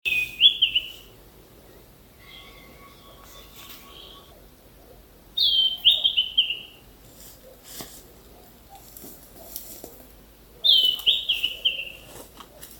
Bailarín Oliváceo (Schiffornis virescens)
Localidad o área protegida: Parque Provincial Cruce Caballero
Condición: Silvestre
Certeza: Observada, Vocalización Grabada
Bailarin-olivaceo-ef.mp3